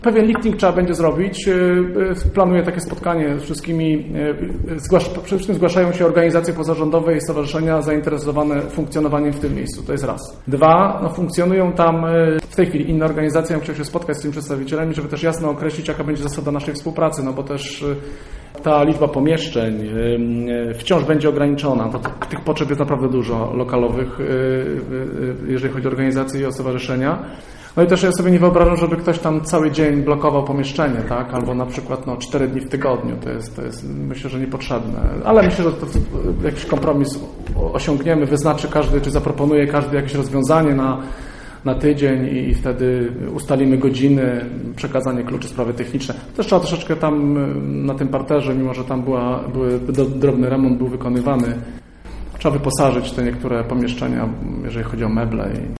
mówił burmistrz Robert Luchowski.